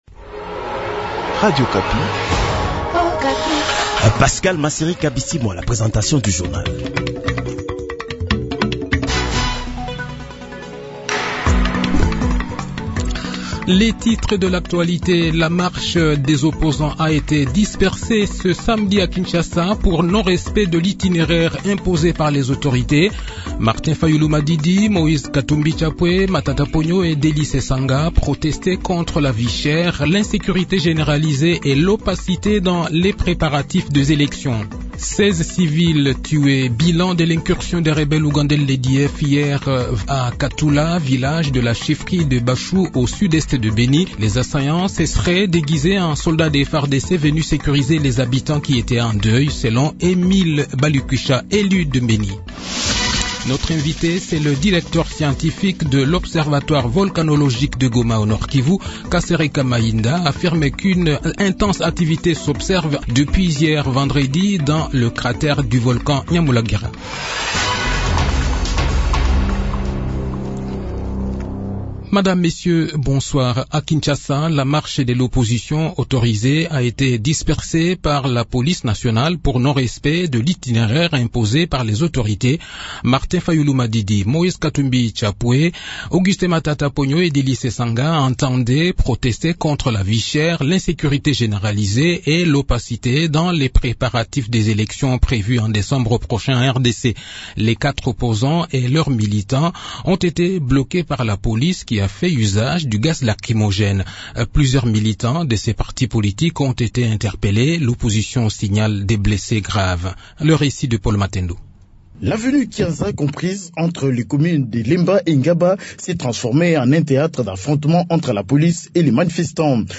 Le journal de 18 h, 20 Mai 2023